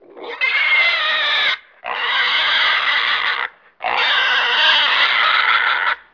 جلوه های صوتی
دانلود صدای حیوانات جنگلی 24 از ساعد نیوز با لینک مستقیم و کیفیت بالا